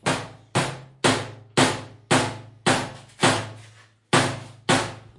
冲击、撞击、摩擦 工具 " 钢桶撞击声
Tag: 工具 工具 崩溃 砰的一声 塑料 摩擦 金属 冲击